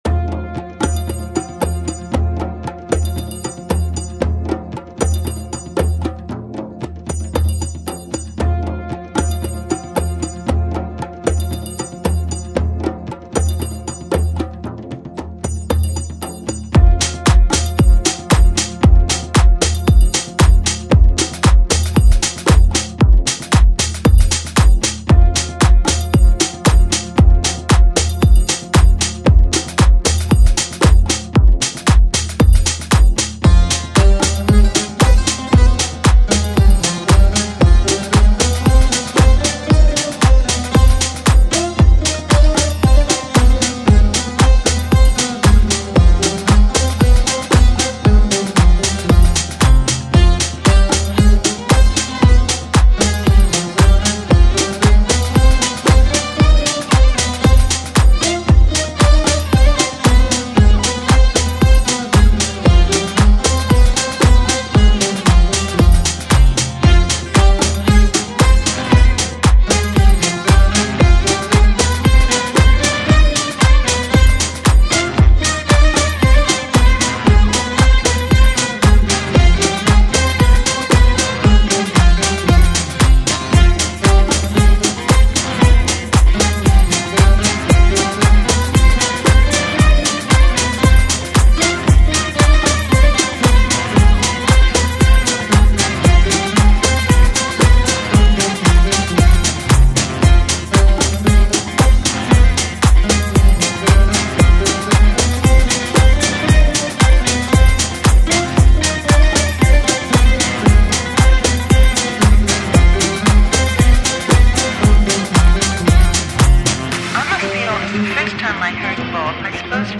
Chill Out, Downtempo, Organic House